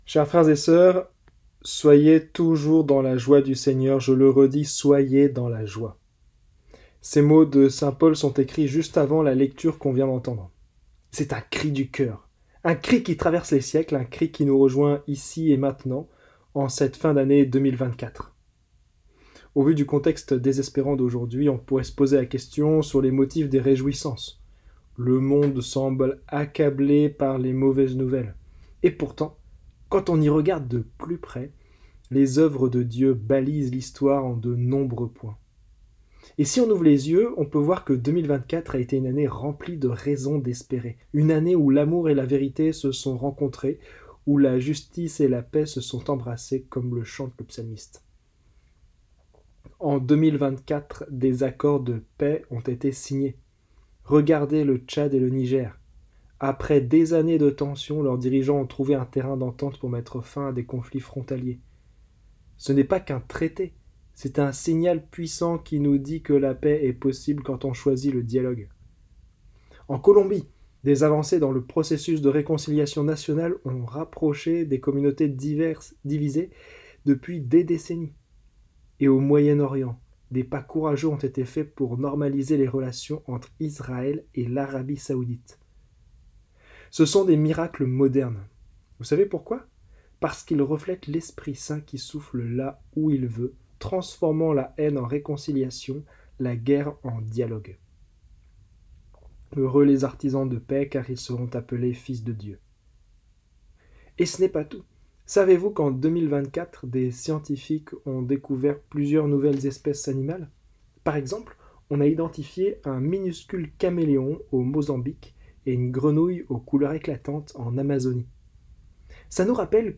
Homélies en 3 points - Messe pour la justice et la paix
Homélies en 3 points